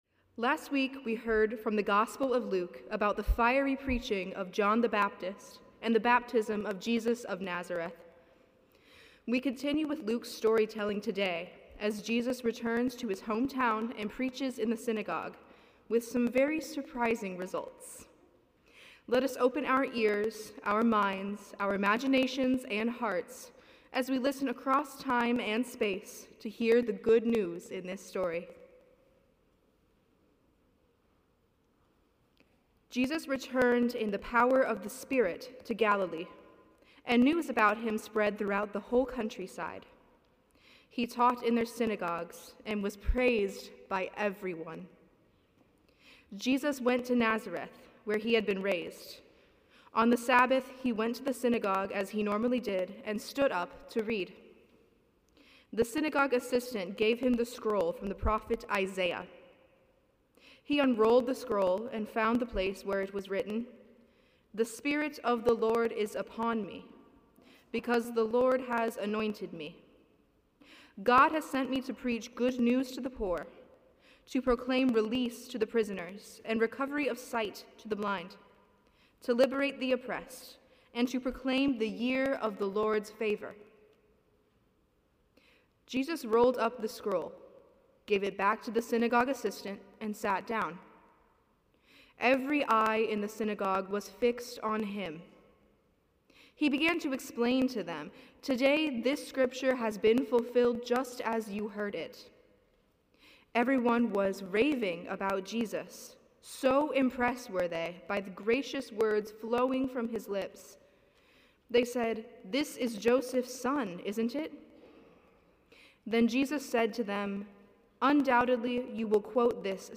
About Us Sermons Living the Word: Bringing Good News.